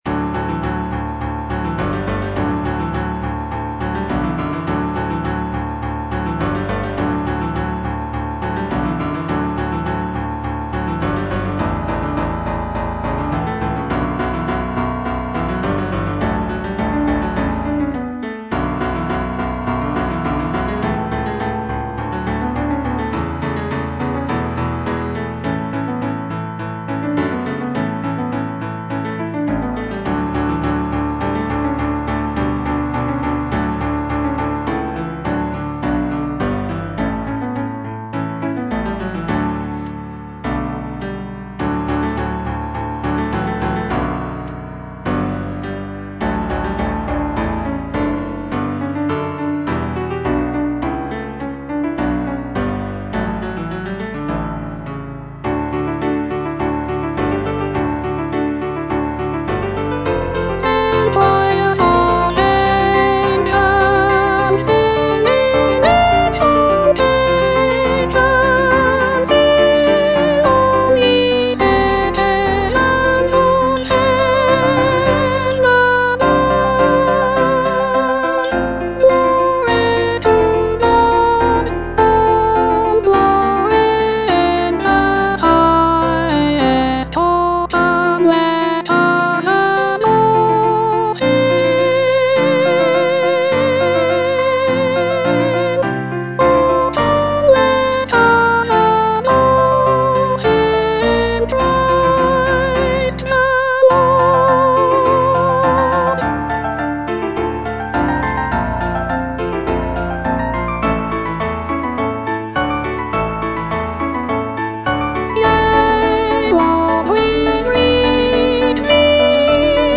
Soprano II (Voice with metronome)
Christmas carol
incorporating lush harmonies and intricate vocal lines.